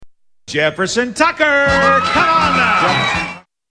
Bob Barker says come on down !
Tags: The New Price Is Right Bob Barker Bob Barker clips Bob Barker says come on down Game show